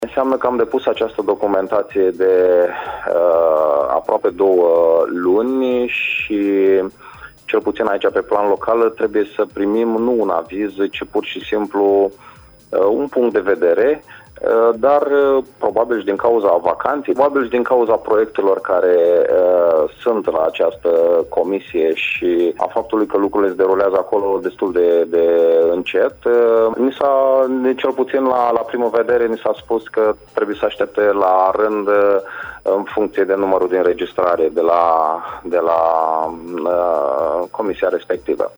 Viceprimarul Radu Botez s-a declarat stupefiat de afirmațiile făcute de Virgil Băbîi, şef al Direcţiei Judeţene pentru Cultură, Culte şi Patrimoniu Naţional Iaşi, comisia zonală a monumentelor istorice.